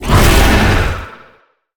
Sfx_creature_hiddencroc_bite_03.ogg